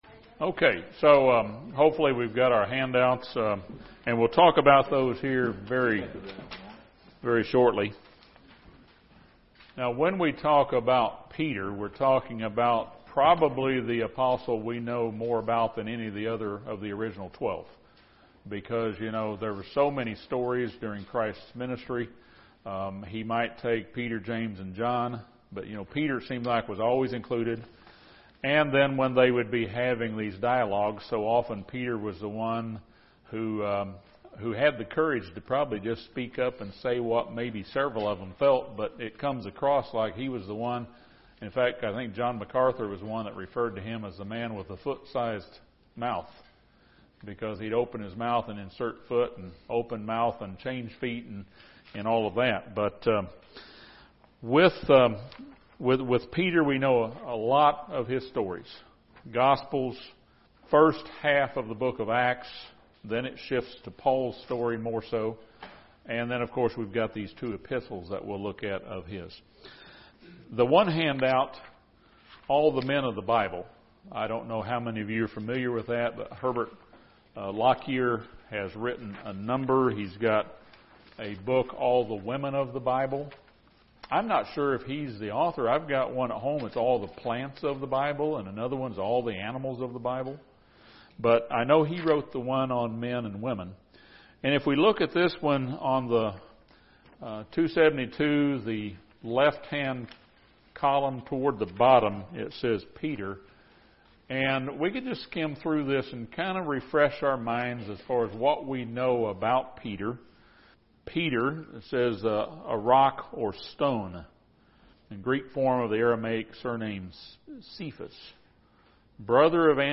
This is the first Bible study on Peter's first epistle. It provides background information about the author, audience and main themes of the book.